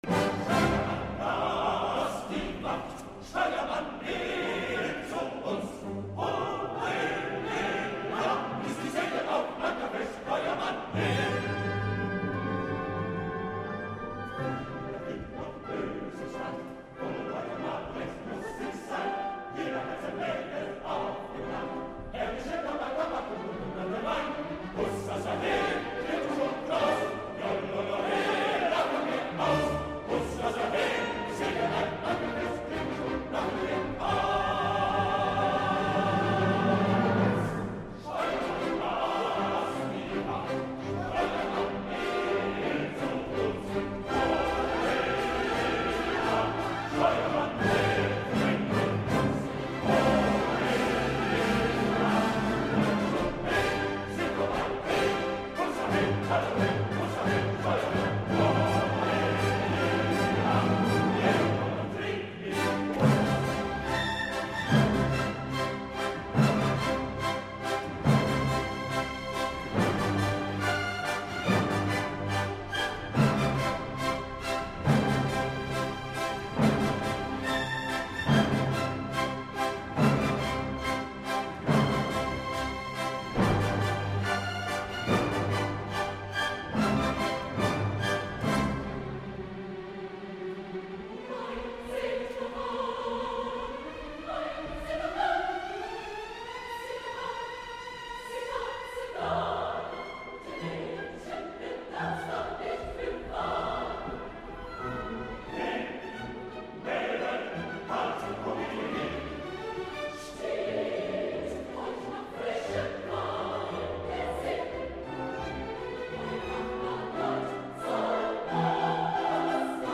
En a mi, que he escoltat a batzegades l’excel·lent retransmissió de Radio Clásica de RNE, no m’ha semblat que ho fos tant, tot i que he de reconèixer que el nivell vocal era molt millor que el d’altres anys i per tant, al menys no podia ser motiu de protesta, tot i que a l’Erik, potser jo li hagués cridat l’atenció.
04-cor-acte-3er.mp3